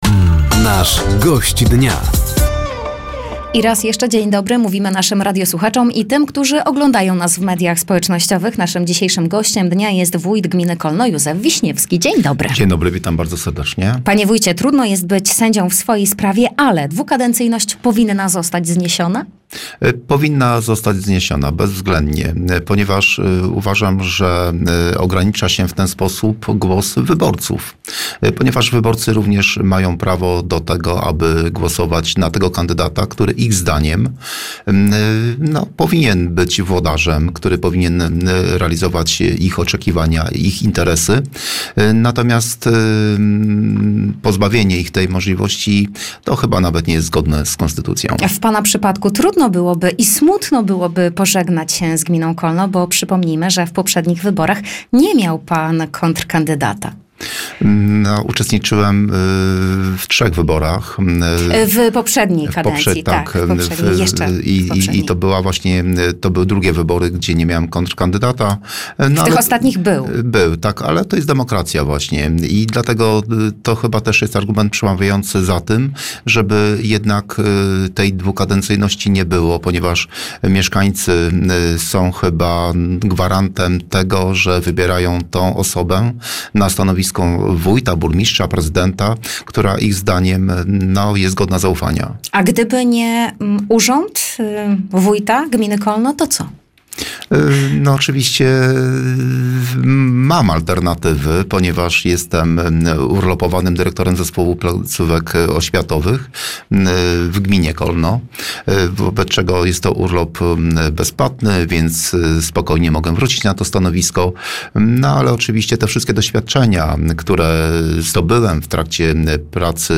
Zapraszamy do wysłuchania rozmowy z wójtem gminy Kolno, Józefem Wiśniewskim.